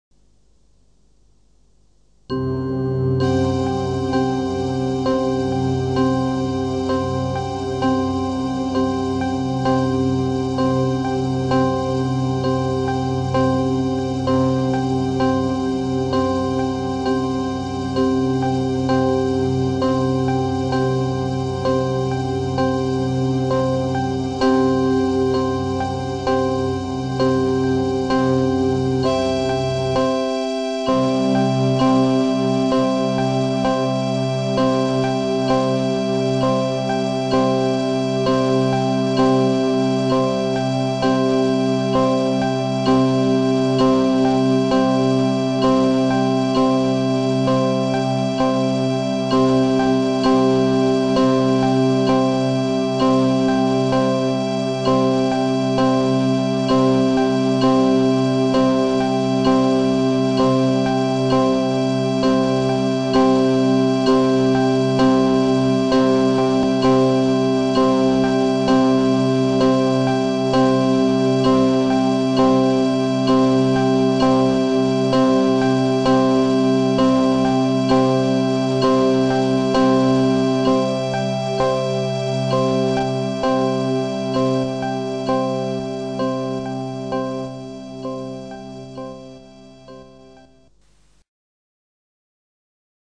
32 : 6 = 3 : 16  zwei Oktaven und Quarte
im Säulentakt erklingen.
Da der Tempel aber nicht allein aus Säulen besteht, legen wir das  Intervall von der Gesamtbreite zur Höhe als liegendes Intervall  unter den Rhythmus.
Zu den Säulen und dem Gesamtintervall der Giebelseite und der Längsseite sind auch die Stufen und das Gaison in der Mitte, unten und oben zu hören.